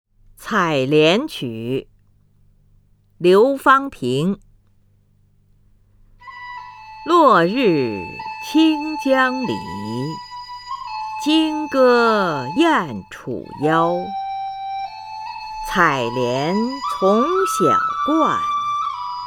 林如朗诵：《采莲曲》(（唐）刘方平) （唐）刘方平 名家朗诵欣赏林如 语文PLUS